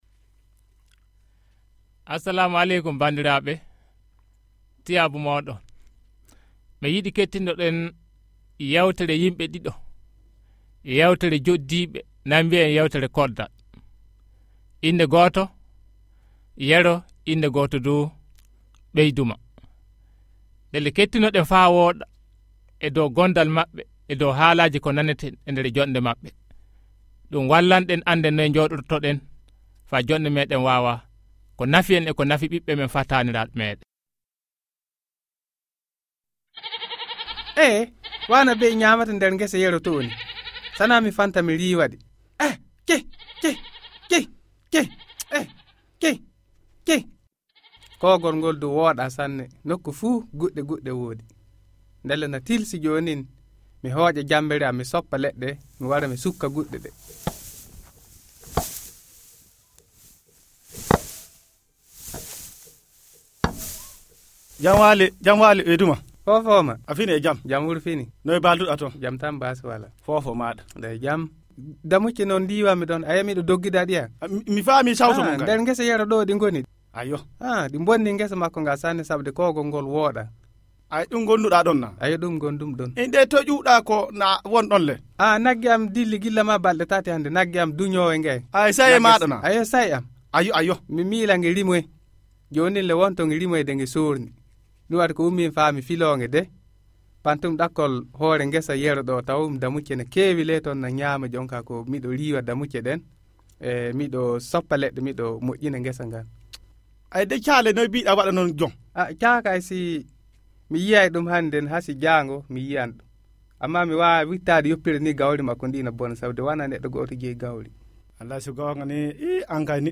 La troupe de théâtre Laawol Dartingol vous présente des pièces de theâtre audio, à écouter en ligne ou à télécharger. Vous allez rencontrer le faux-type Baa Moumouni, la feroce Inna Moumouni et leur pauvre ami Bogga.